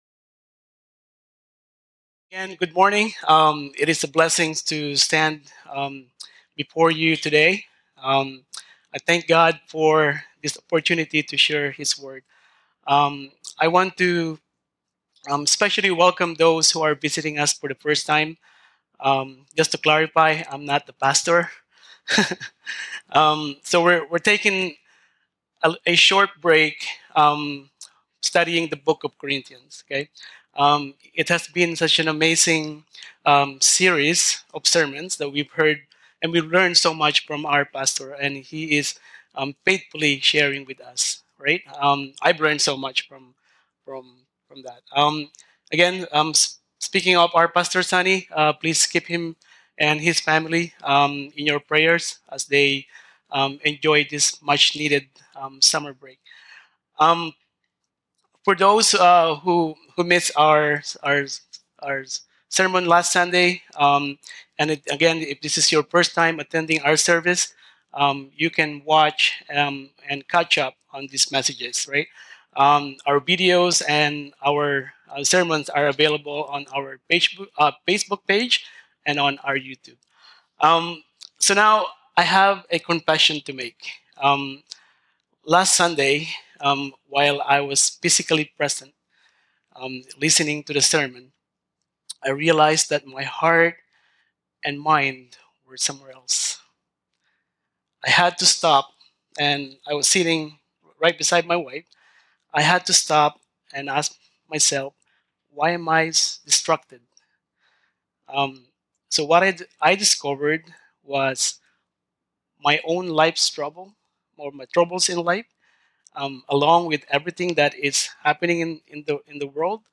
Glencairn Baptist Church – God’s Power in Our Weakness